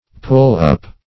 pull-up - definition of pull-up - synonyms, pronunciation, spelling from Free Dictionary